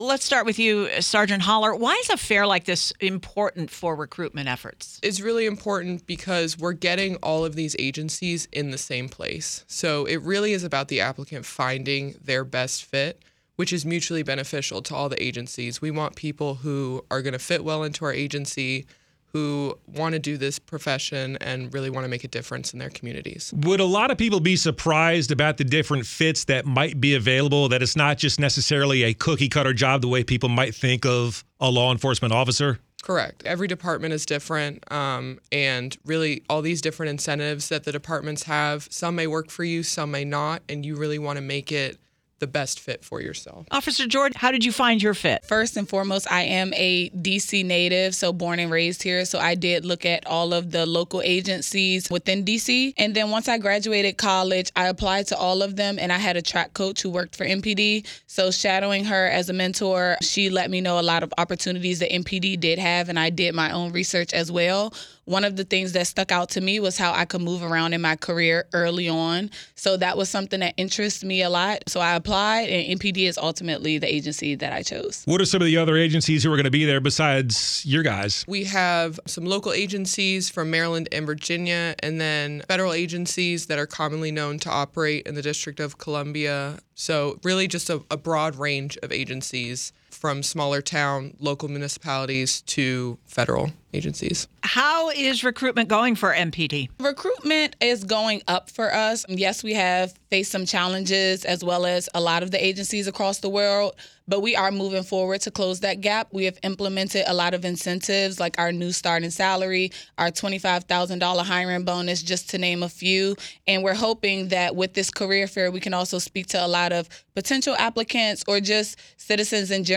speak with MPD officers about recruitment